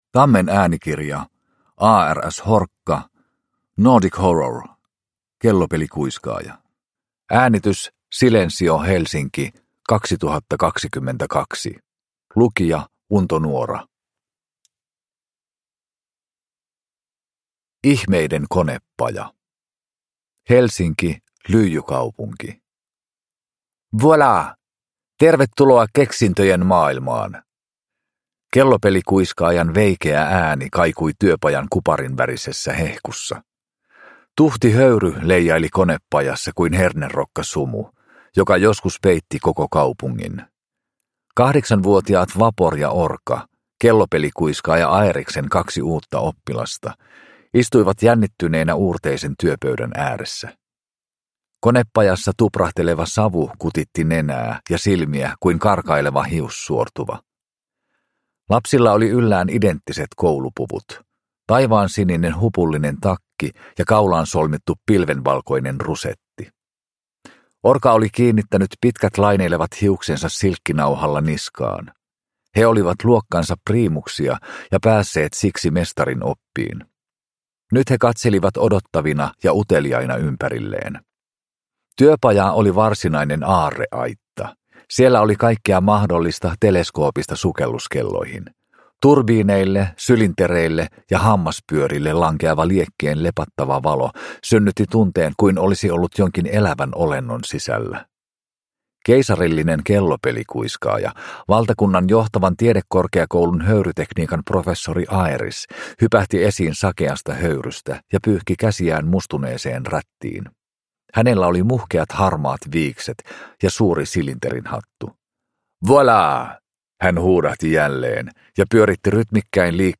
Kellopelikuiskaaja – Ljudbok – Laddas ner